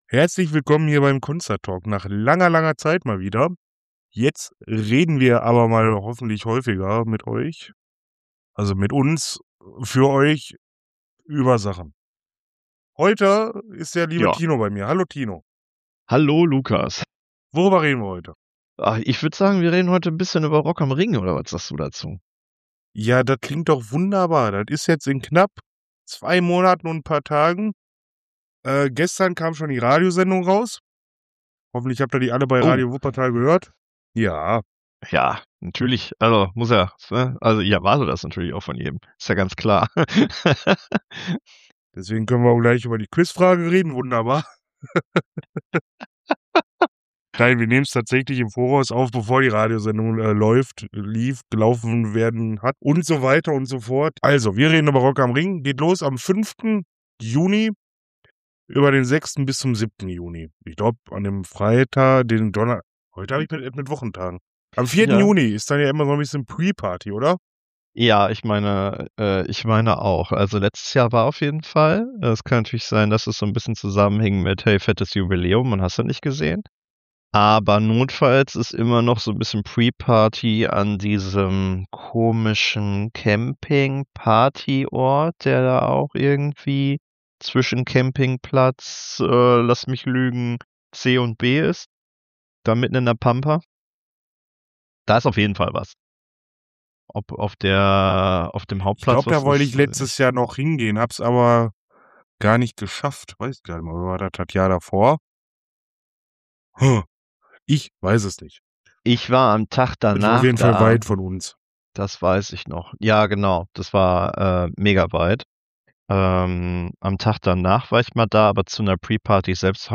Es geht um Line-up, Timetable-Stress, unsere Must-Sees und natürlich die wichtigen Festival-Themen wie Lidl, Camping und spontanes Chaos. Locker, bisschen planlos und genau so, wie man halt übers Festival quatscht